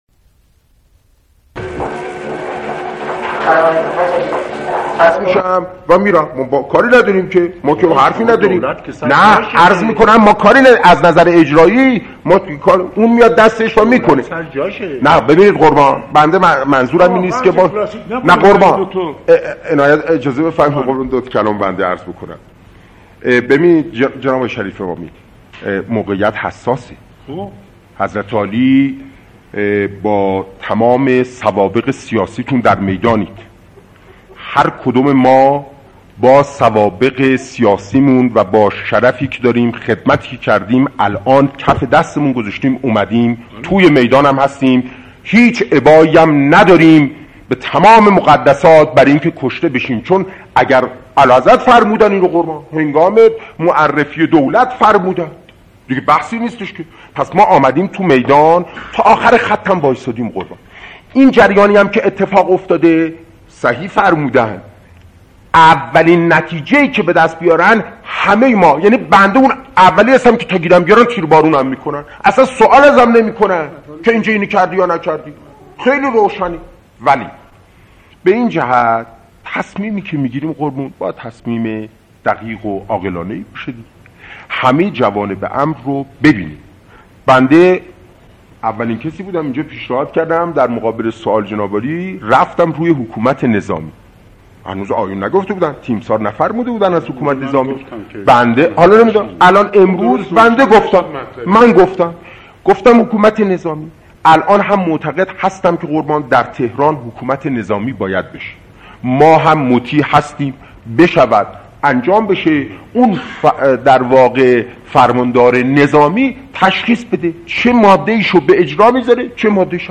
دانلود فایل دانلود فایل دانلود فایل دانلود فایل نوارجلسه مهم شورای عالی امنیت کشور در روز شانزدهم شهریور 1357 به ریاست جعفر شریف امامی نخست وزیر وقت رژیم پهلوی در اختیار موسسه مطالعات و پژوهش های سیاسی قرارگرفت . این سند صوتی بازتاب دهنده موضوعاتی است که در جلسه شورای امنیت درست یک روز قبل از حوادث خونین 17 شهریور 1357 مطرح شده ، نخستین بار جزئیات تصمیم گیری در باره دلایل اعلام حکومت نظامی در تهران و چند شهر مهم ایران اعلام می شود.